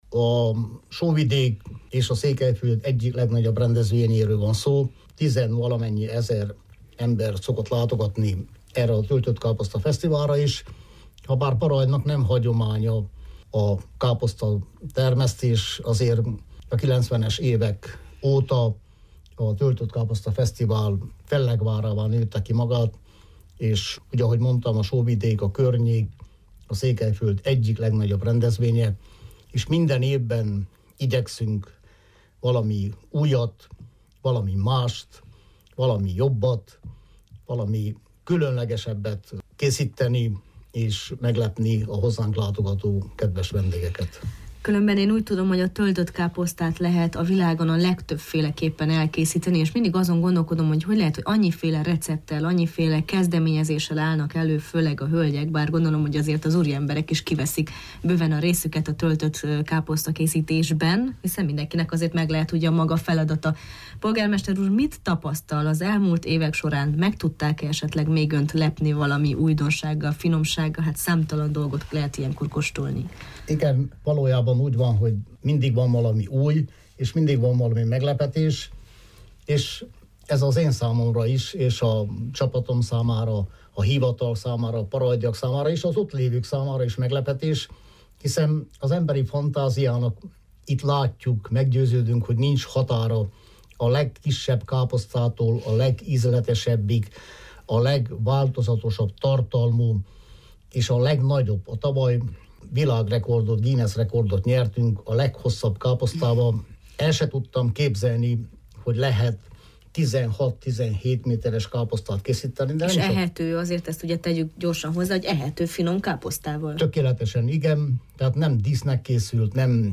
A mai Jó reggelt, Erdély! vendége Bokor Sándor, Parajd polgármestere volt.